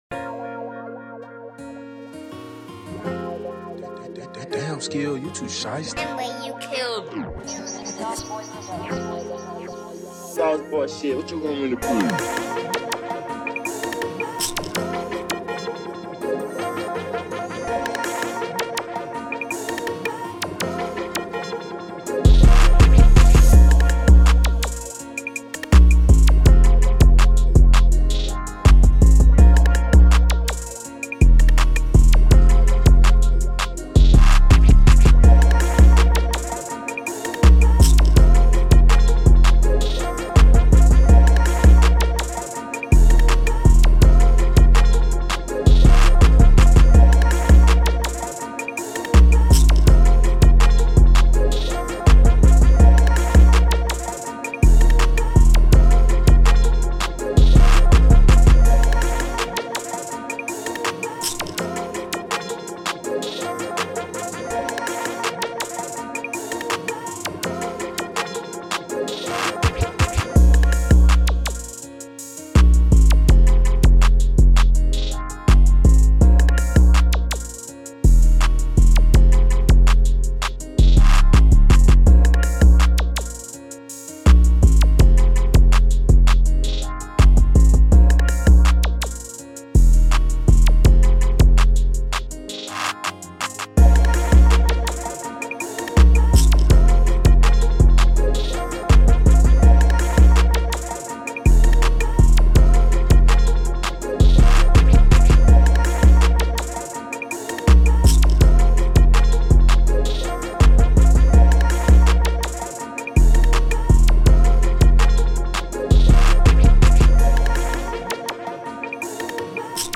Here's the official instrumental